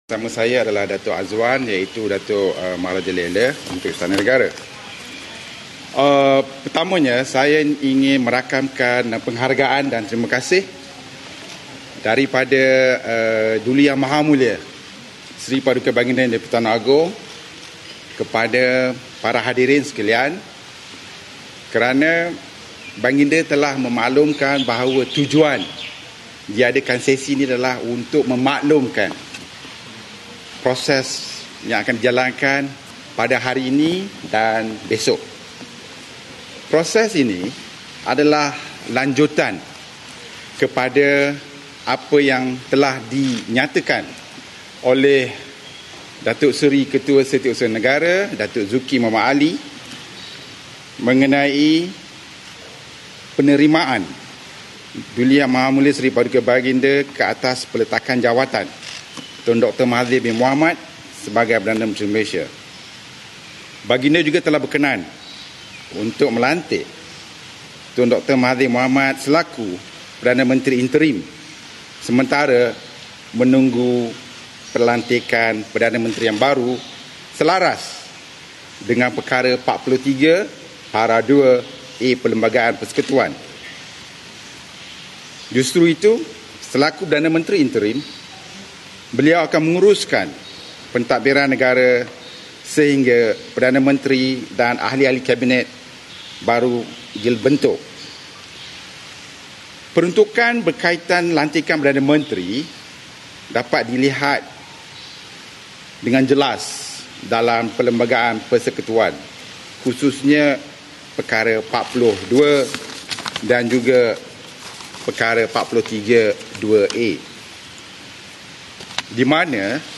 [SIDANG MEDIA] 221 Ahli Parlimen kecuali Tun M dititah menghadap Agong hari ini dan esok